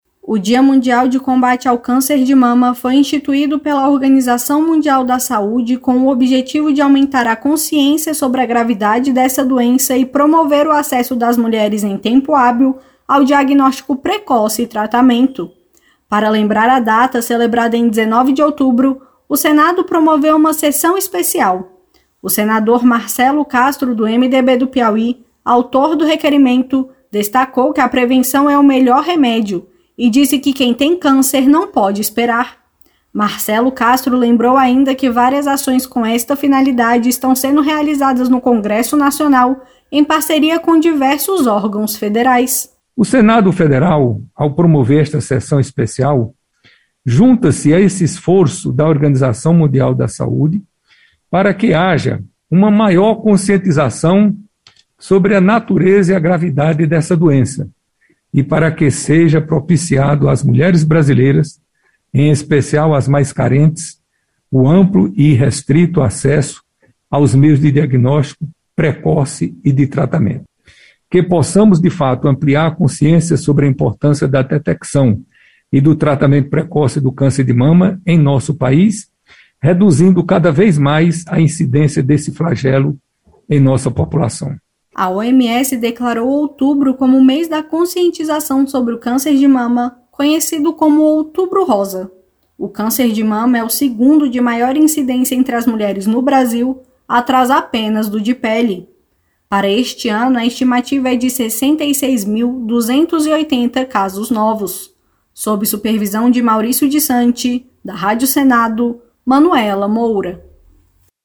O Senado promoveu sessão especial remota em comemoração ao Dia Mundial de Combate ao Câncer de Mama. O senador Marcelo Castro (MDB/PI), um dos autores do requerimento, destacou que a prevenção é o melhor remédio e lembrou que quem tem câncer não pode esperar.